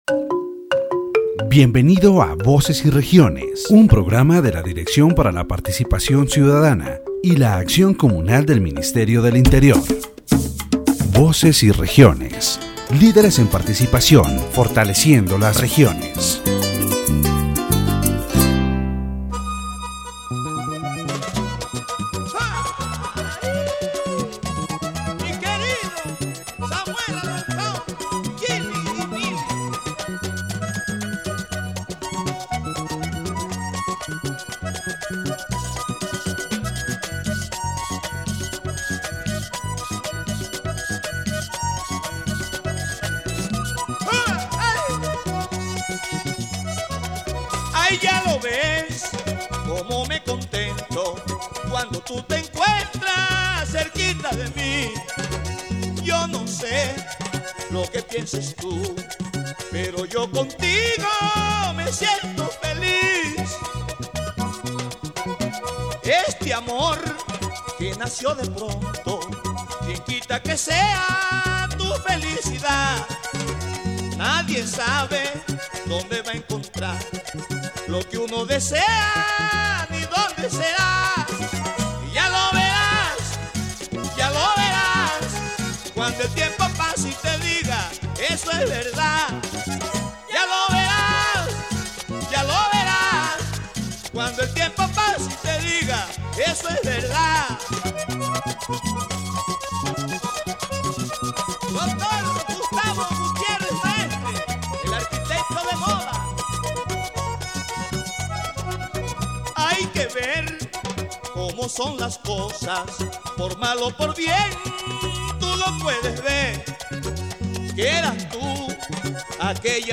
The program “Voces y Regiones”, broadcast by Laud Estéreo 90.4 FM, addressed the issue of mining exploitation in La Guajira and its social, environmental and cultural impact.